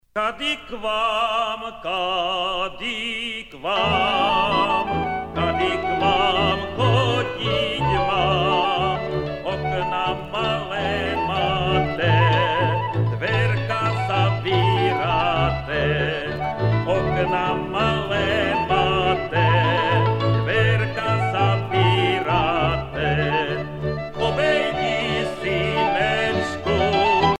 Folk singer from Moravian Wallachia
Pièce musicale éditée